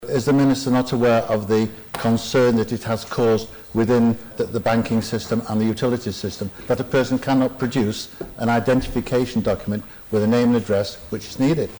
That's Ramsey MHK Leonard Singer who's unhappy that businesses, and the public, weren't consulted before the Department of Infrastructure changed the system.